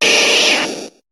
Cri de Carabaffe dans Pokémon HOME.